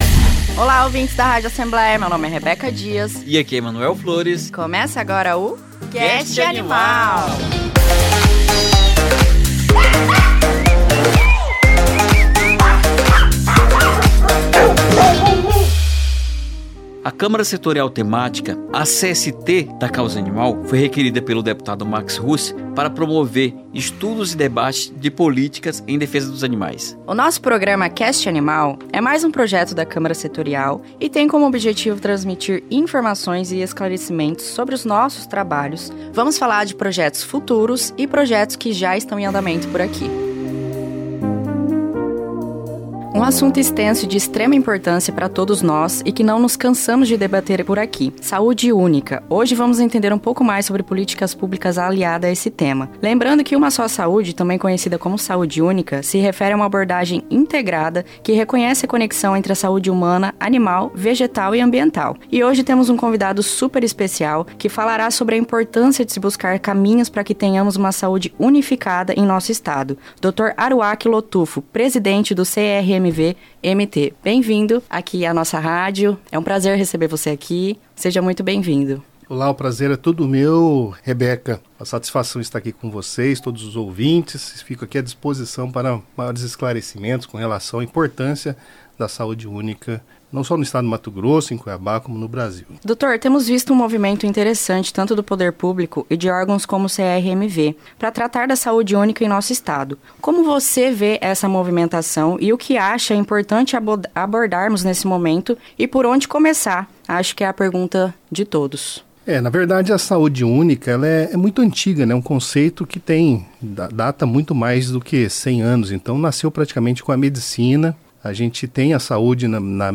Hoje temos um convidado que falará sobre a importância de se buscar caminhos para que tenhamos uma Saúde unificada em nosso Estado: